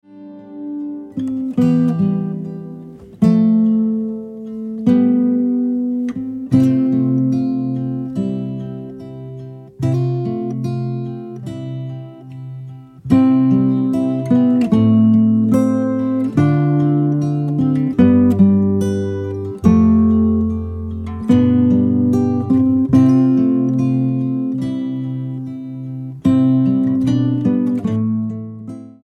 STYLE: Pop
instrumental renditions of carols
a pleasant and relaxing collection